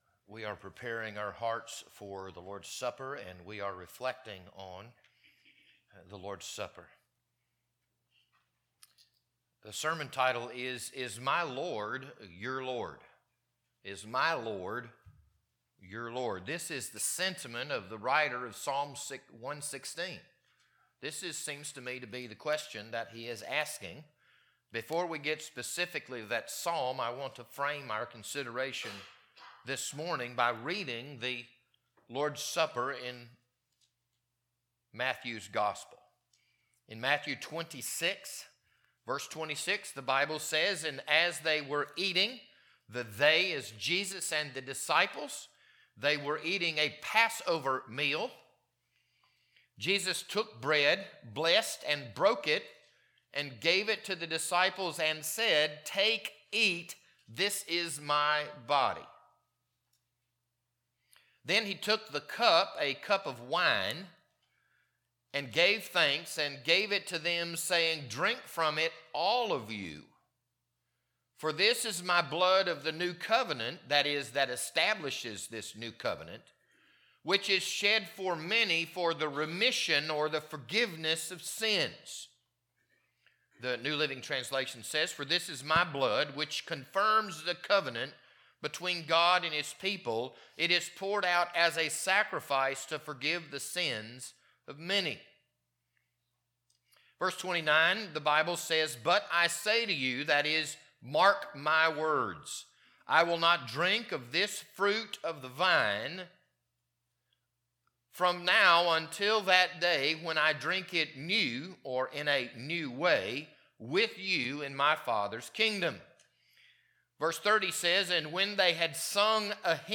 This sermon was recorded on Sunday morning, February 15th, 2026.